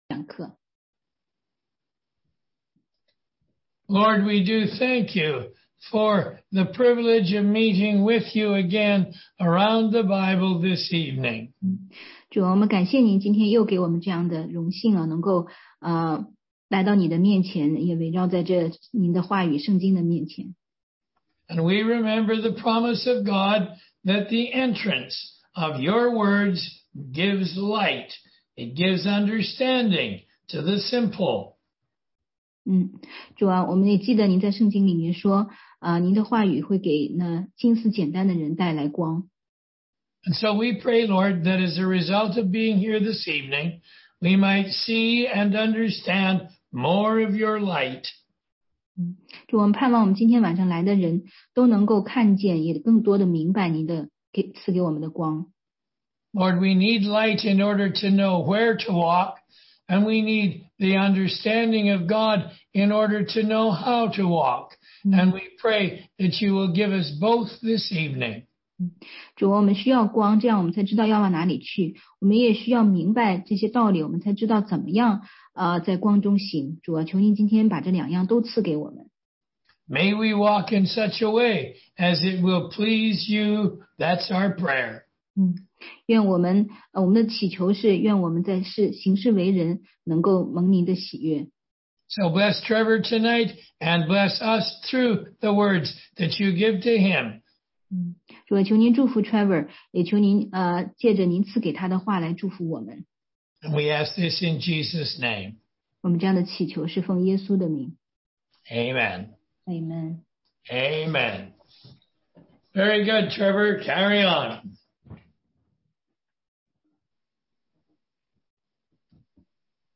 16街讲道录音 - 读经的方法和原则系列之十二：新约使用比喻等修辞手法引用旧约
中英文查经